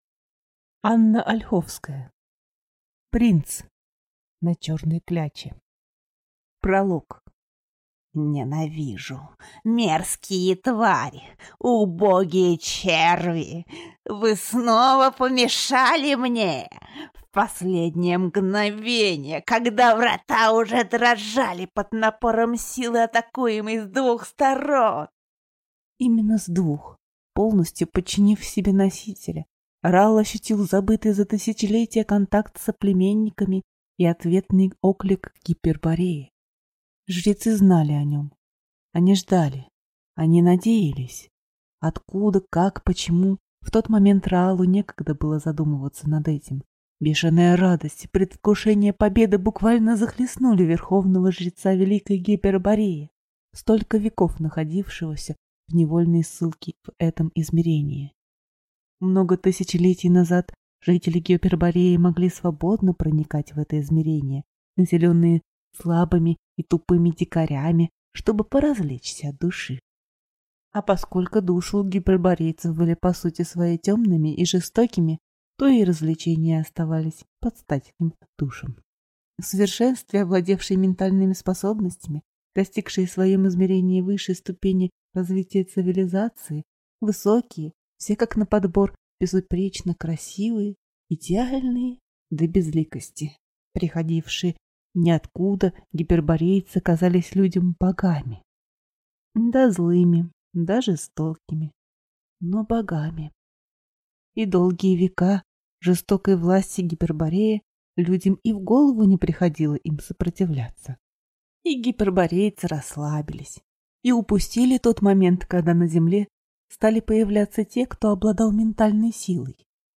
Aудиокнига Принц на черной кляче Автор Анна Ольховская